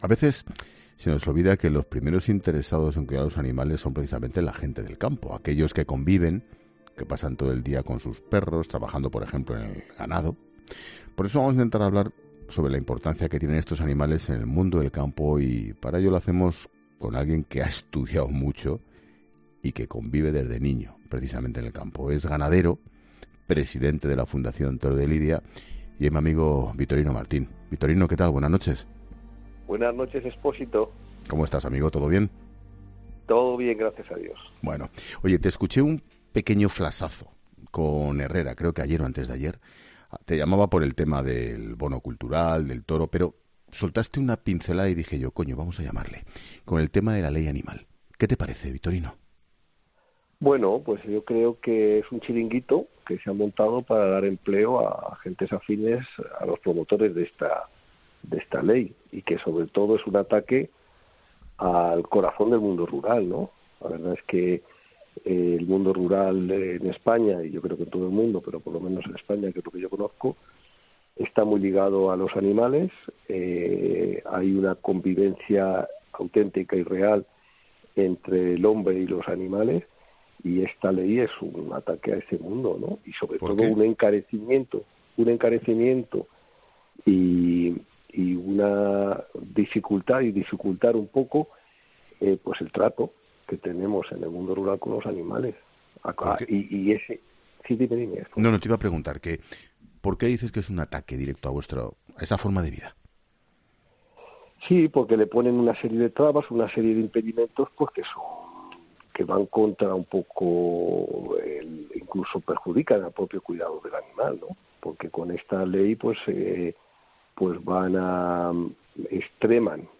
El ganadero Victorino Martín se ha mostrado en 'La Linterna' muy crítico con la ley aprobada hoy en el Congreso, que cree que traerá muchas trabas para tener animales en el campo